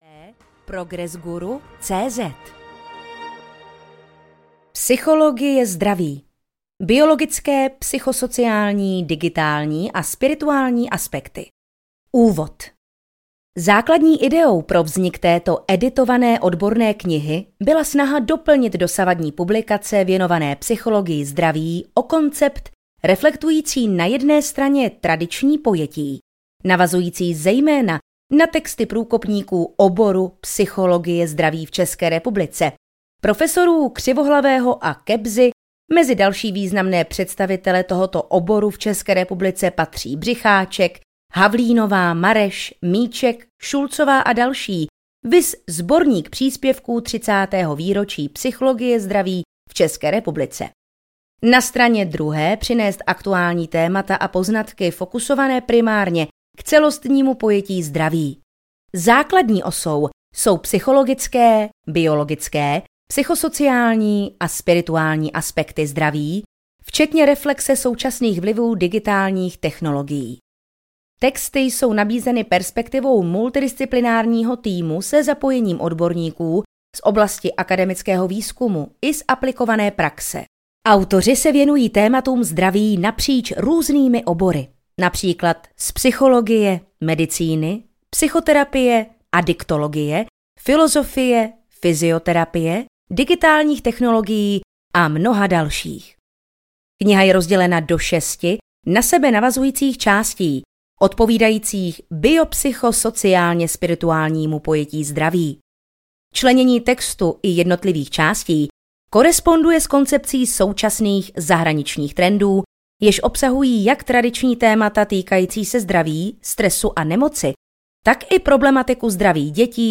Psychologie zdraví audiokniha
Ukázka z knihy
psychologie-zdravi-audiokniha